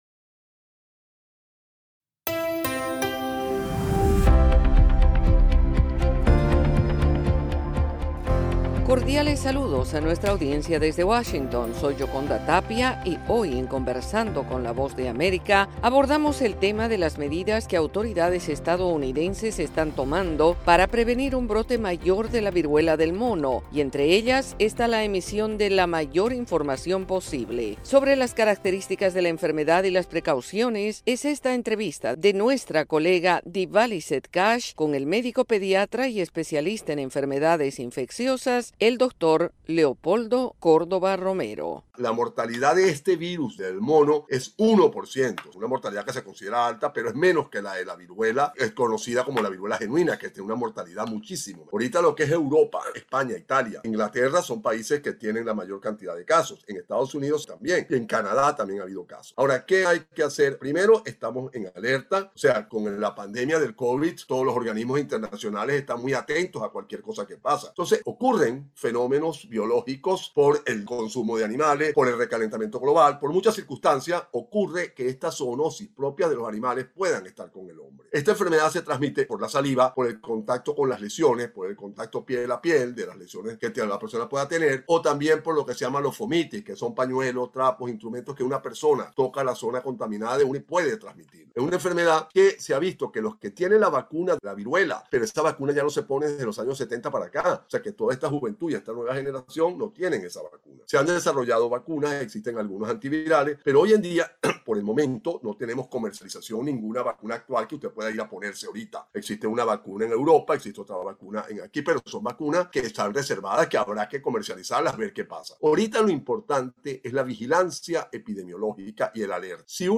Conversando con la VOA